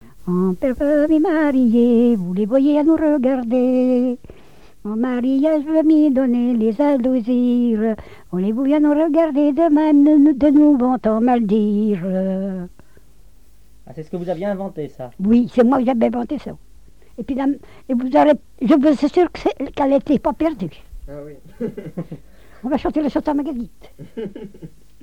collecte en Vendée
répertoire de chansons, et d'airs à danser
Pièce musicale inédite